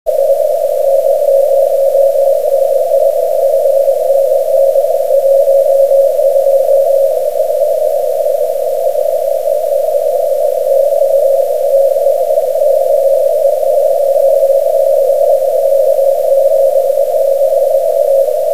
・08z過ぎ、ゆっくりしたキーイングが弱いながらも安定して聞こえる。